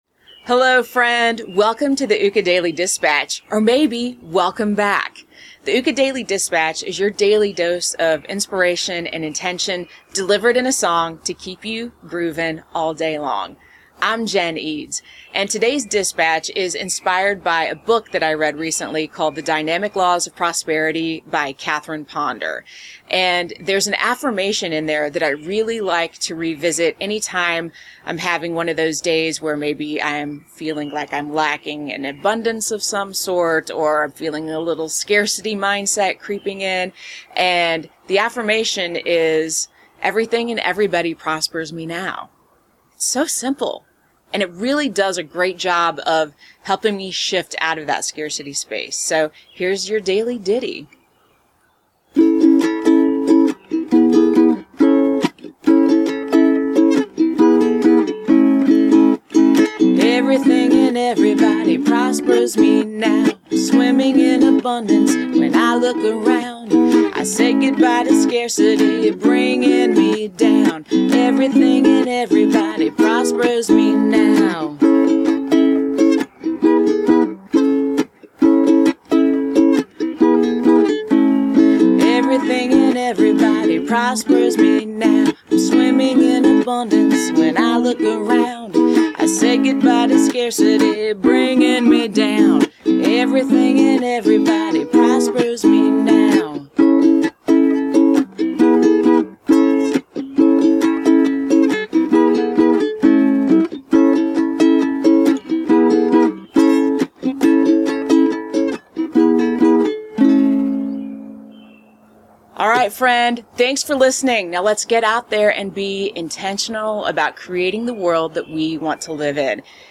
This prosperity mantra song was inspired by the book "The Dynamic Laws of Prosperity" by Catherine Ponder.